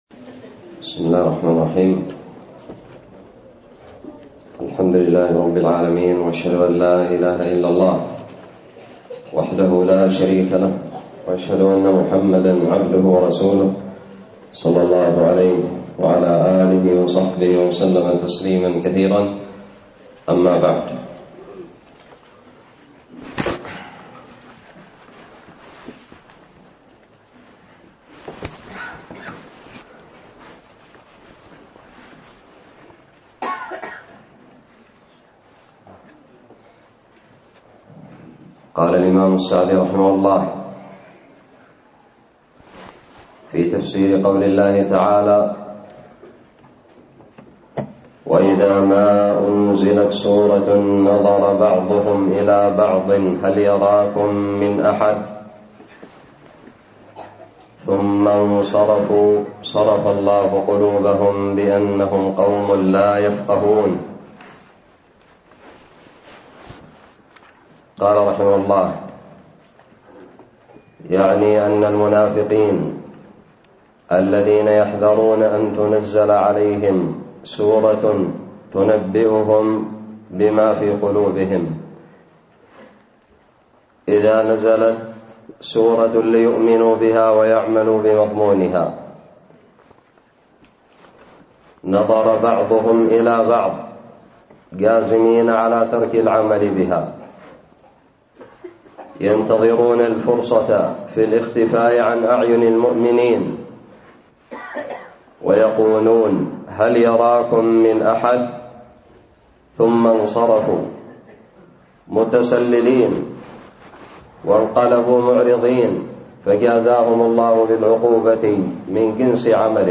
الدرس الخامس والخمسون والأخير من تفسير سورة التوبة
ألقيت بدار الحديث السلفية للعلوم الشرعية بالضالع